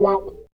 28 GUIT 2 -R.wav